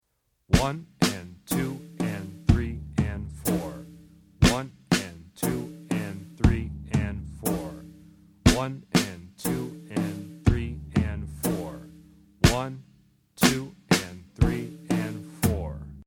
Drum Set Level